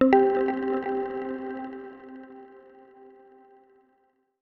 Ethereal_mallet_5.wav